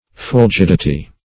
Fulgidity \Ful*gid"i*ty\, n.
fulgidity.mp3